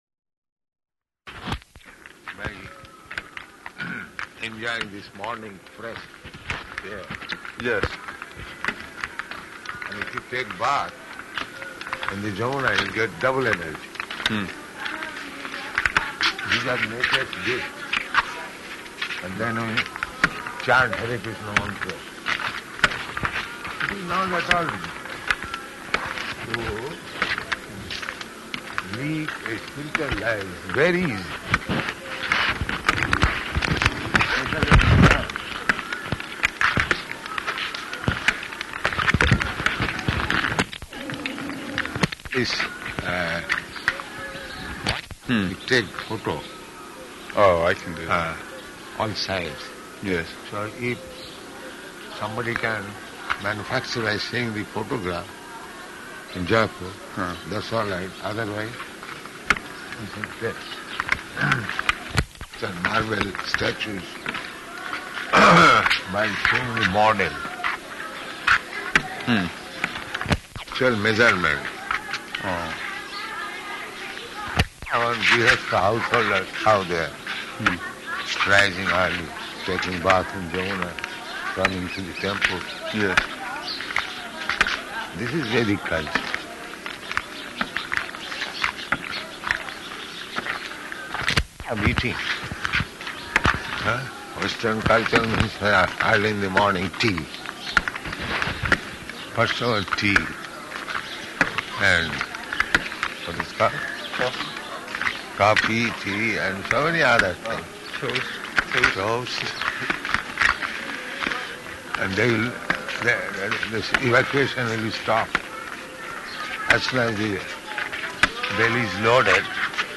Morning Walk, partially recorded
Type: Walk
Location: Vṛndāvana